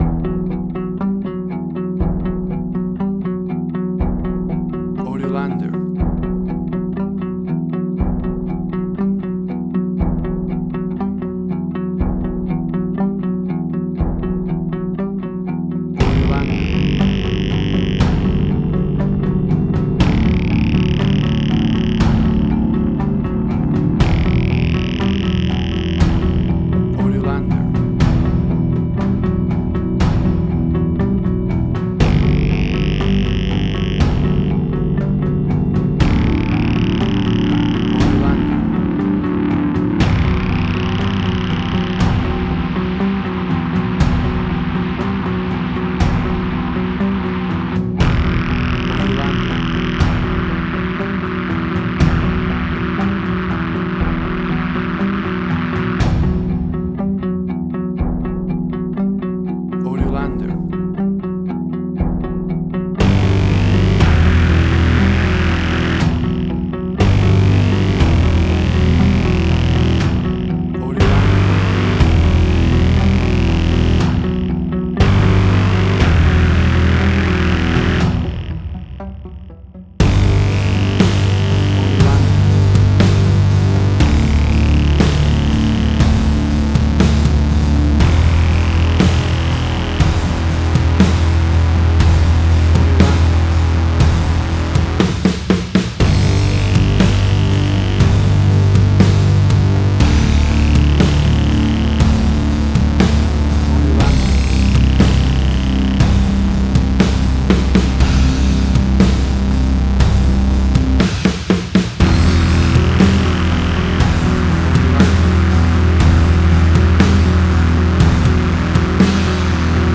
Post-Electronic.
WAV Sample Rate: 16-Bit stereo, 44.1 kHz
Tempo (BPM): 60